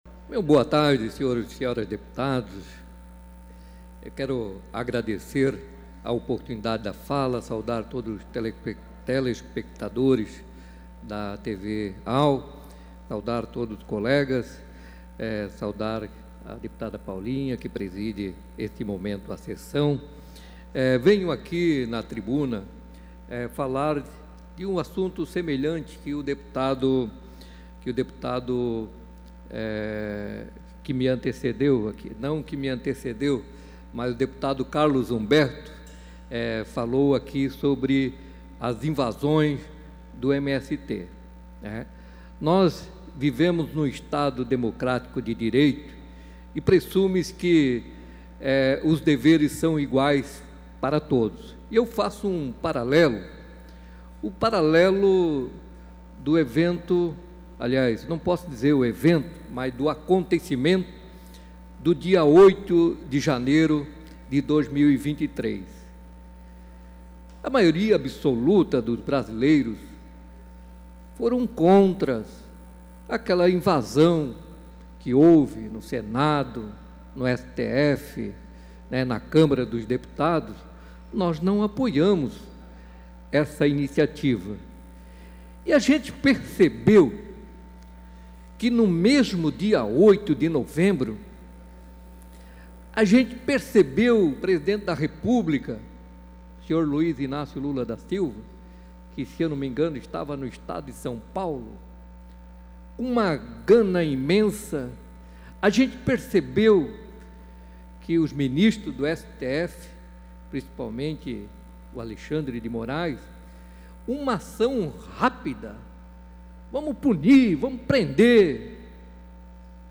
Pronunciamentos da sessão ordinária desta terça-feira (7)